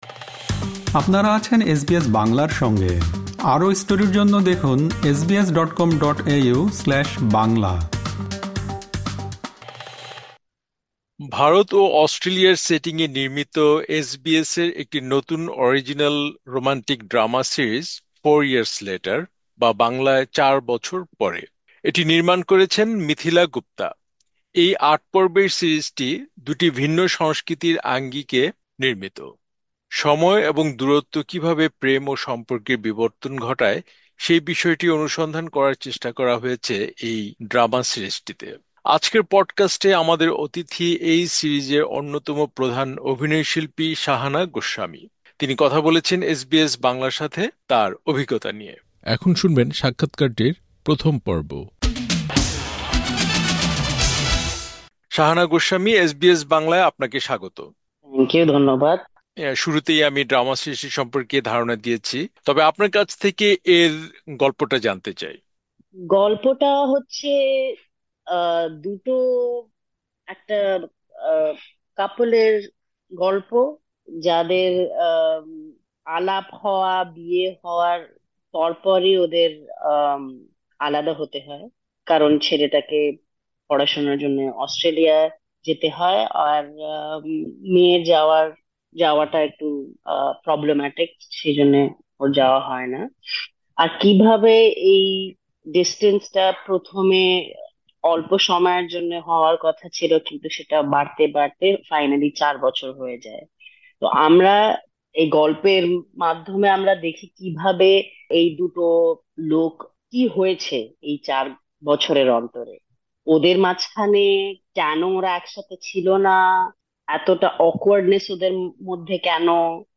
আজকের পডকাস্টে, আমাদের অতিথি এই সিরিজের অন্যতম প্রধান অভিনয় শিল্পী শাহানা গোস্বামী। তিনি কথা বলেছেন এসবিএস বাংলার সাথে, তার অভিজ্ঞতা নিয়ে। এখানে প্রকাশিত হল সাক্ষাৎকারটির প্রথম পর্ব।